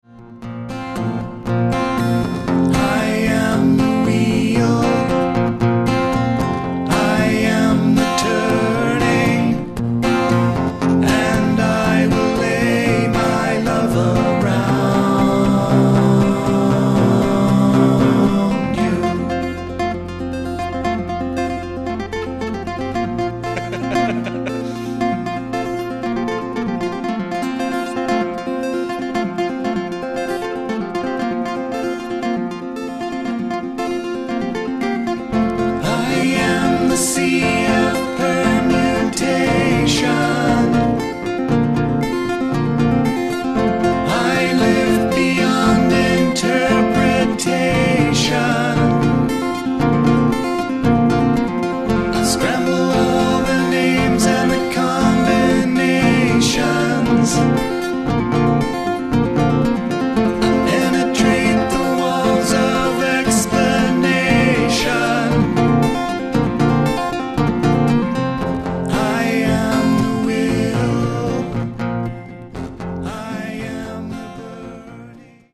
acoustic guitar, vox
bass, glass
acoustic guitar, backing vox